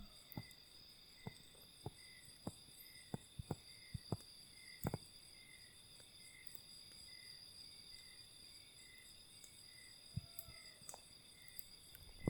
Lechuza Listada (Strix hylophila)
Nombre en inglés: Rusty-barred Owl
Localidad o área protegida: Reserva Papel Misionero
Condición: Silvestre
Certeza: Vocalización Grabada